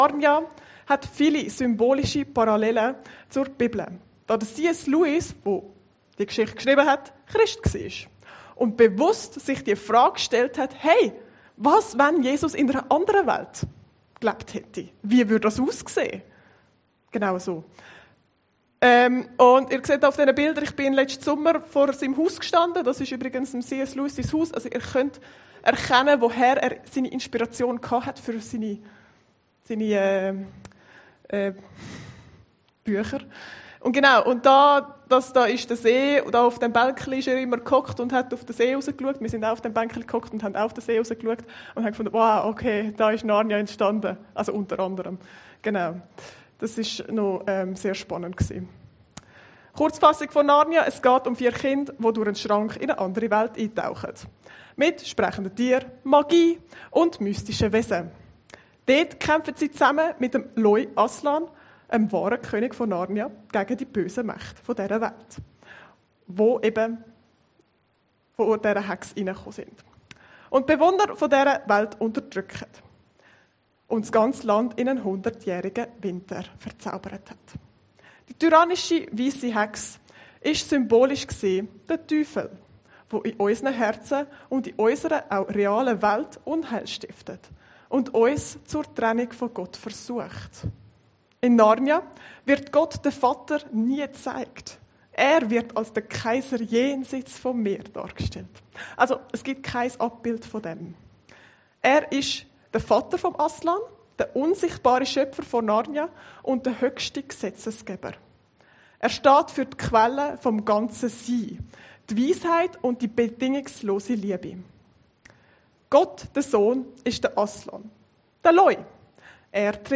Predigten Heilsarmee Aargau Süd – Heiliger Geist - Erlebst du Ihn?